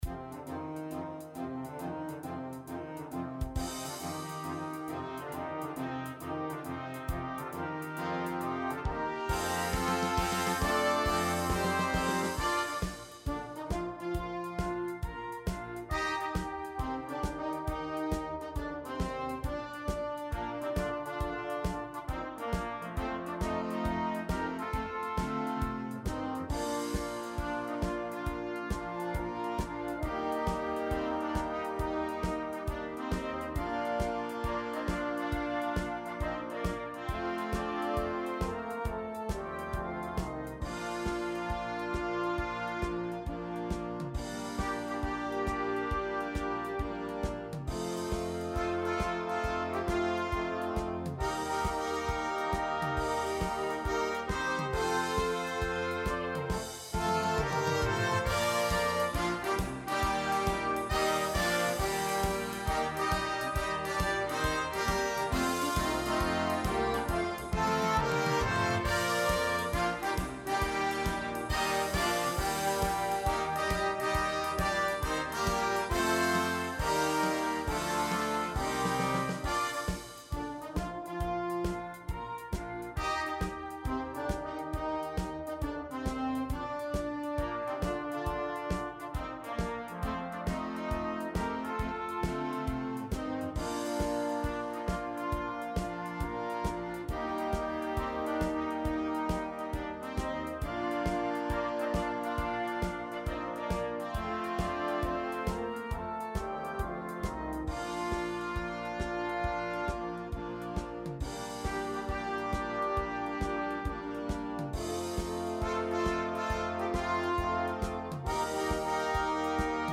Type de formation : Fanfare / Harmonie / Banda
Pré-écoute non téléchargeable · qualité réduite